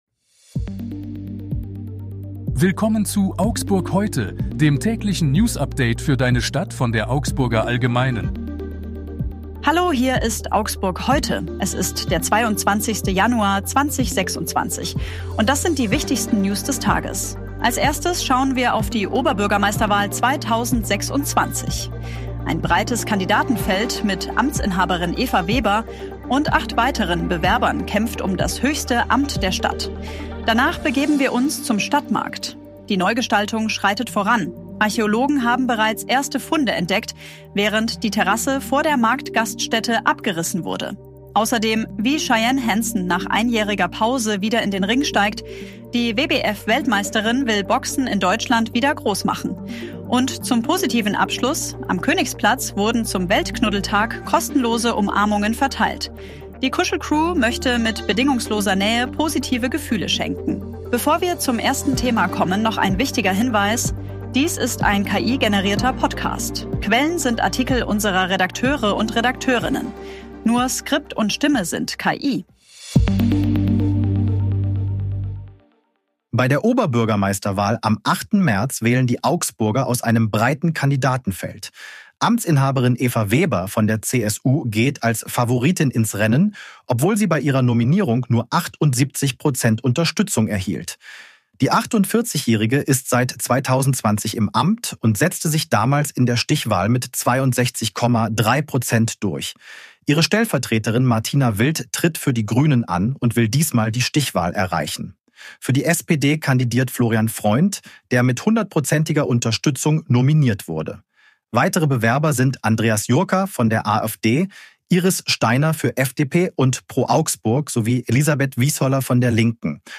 Stimme sind KI.